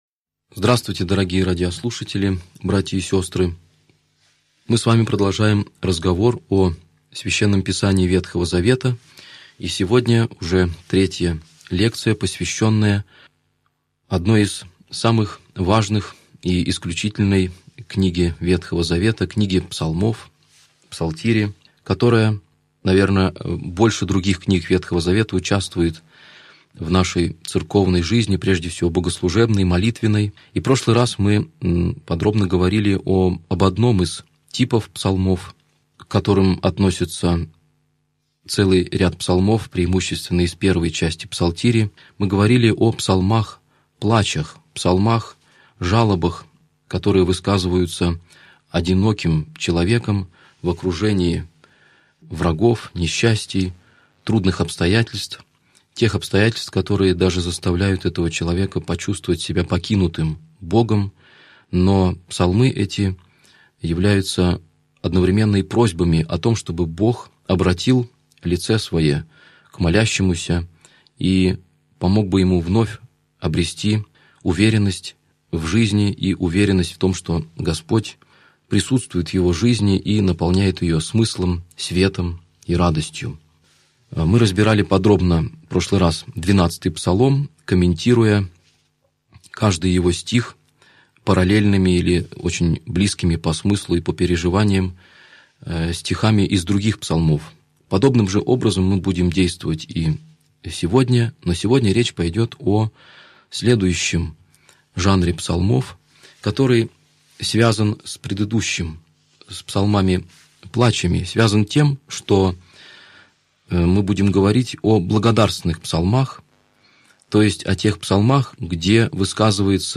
Аудиокнига Лекция 32. Псалмы царские | Библиотека аудиокниг